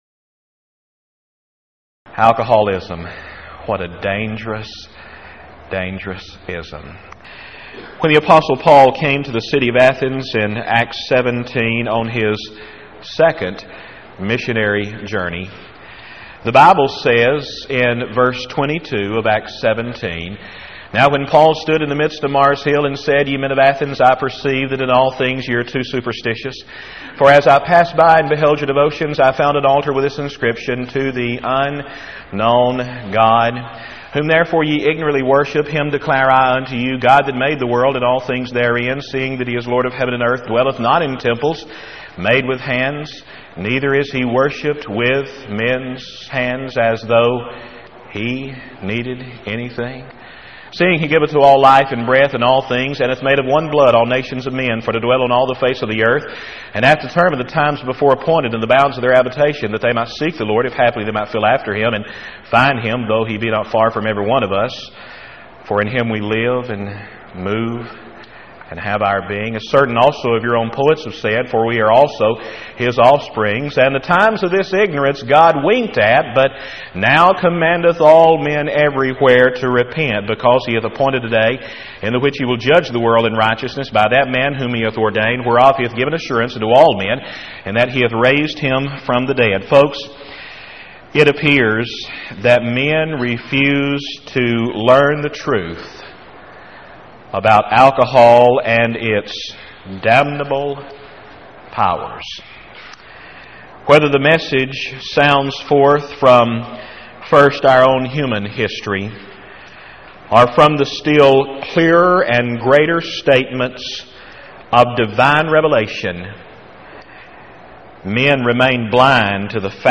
Event: 1997 Power Lectures
lecture